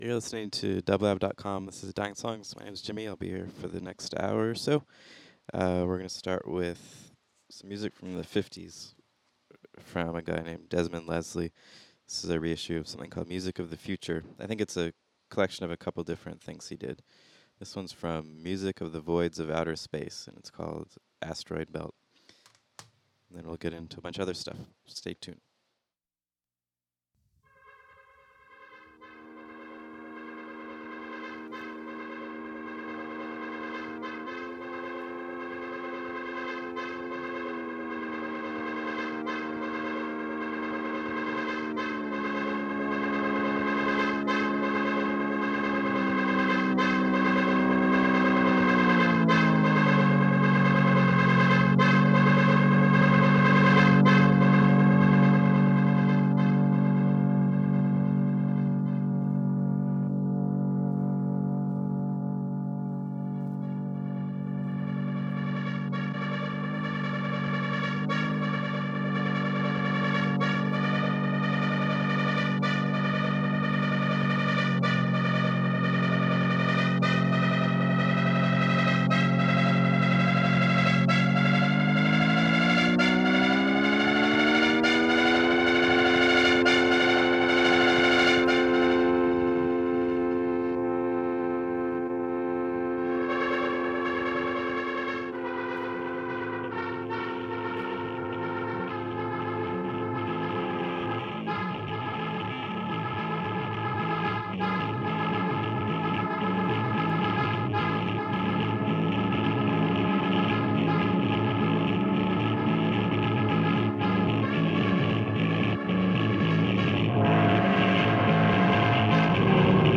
I like stuff that’s dreamy, ghostly, pretty and sad.
Alternative Ambient Goth Techno